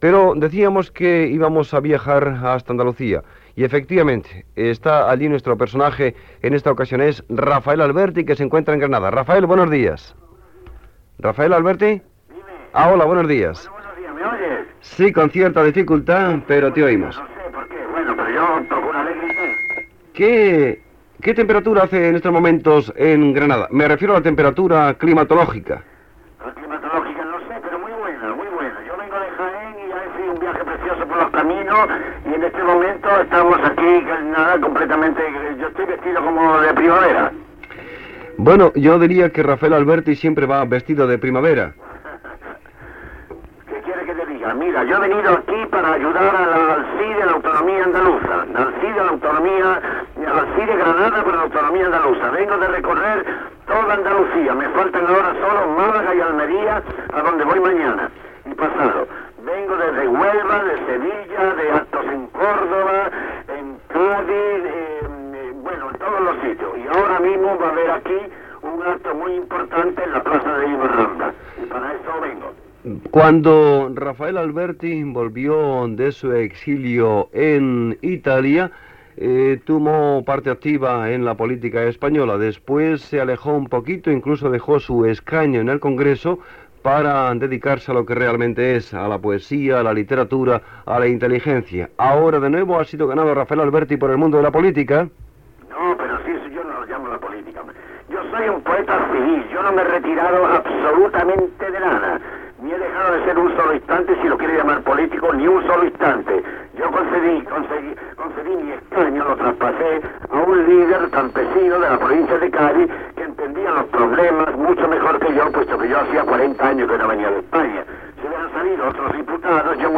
Entrevista telefònica al poeta Rafael Alberti que es troba a la ciutat de Granada, demanant el sí per al Referèndum d'Autonomia d'Andalusia del dia 28 de febrer de 1980